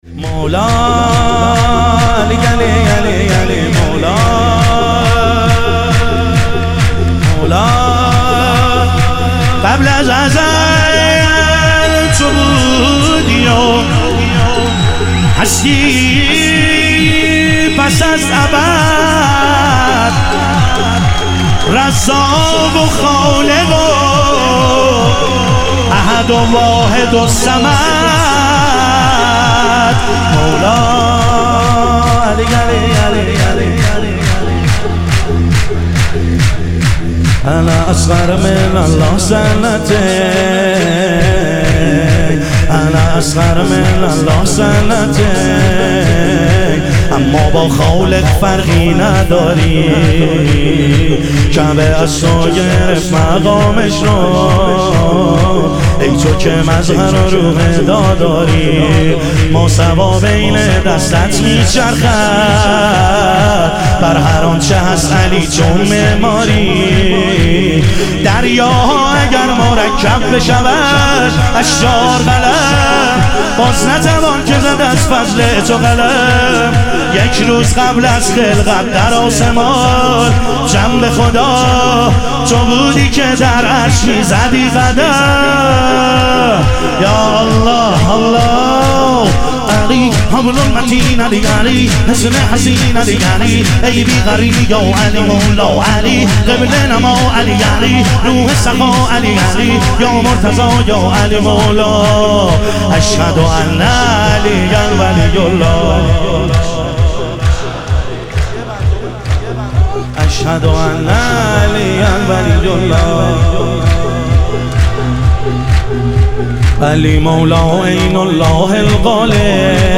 ظهور وجود مقدس امام حسین علیه السلام - شور